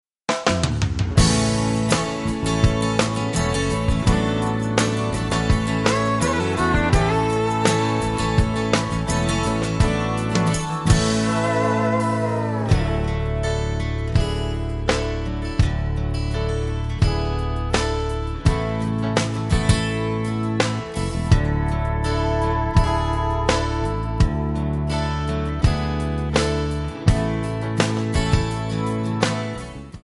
MPEG 1 Layer 3 (Stereo)
Backing track Karaoke
Country, 1980s